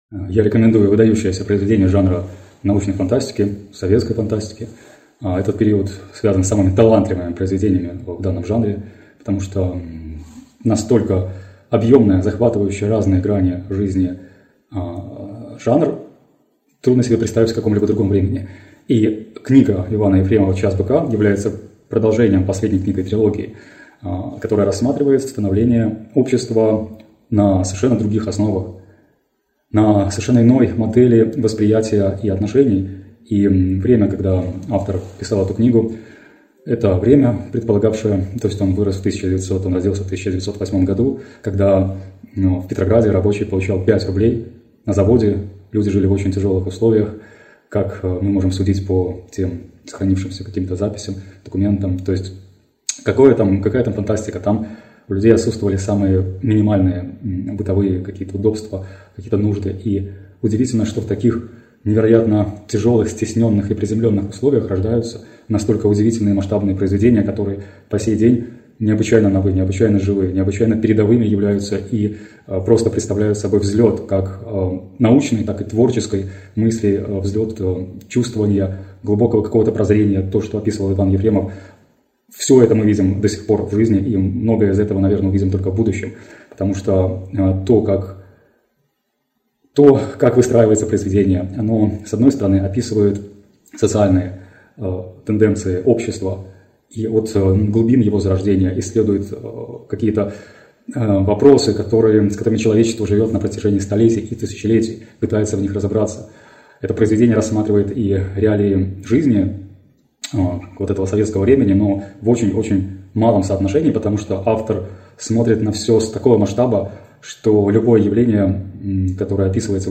Голосовая заметка - Иван Ефремов Тег audio не поддерживается вашим браузером.